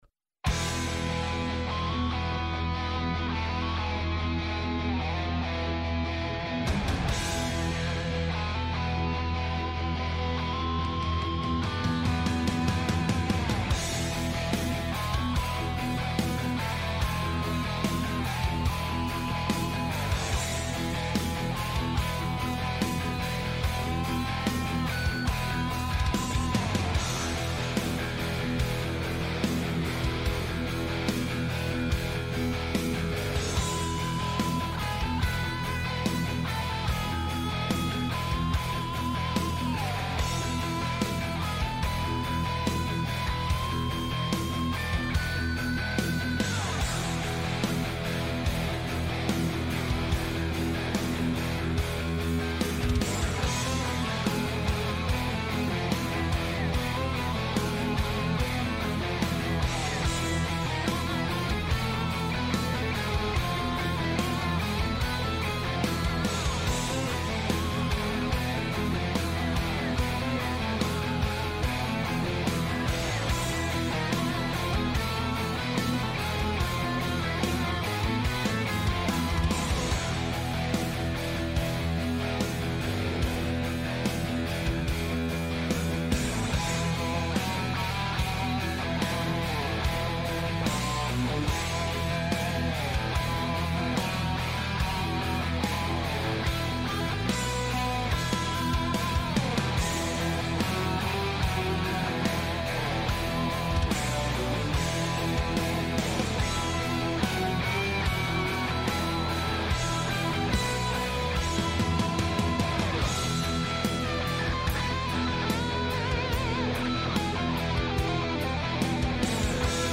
Επιτρέπεται μάλιστα η είσοδος στο στούντιο σε κάθε λογής περαστικούς!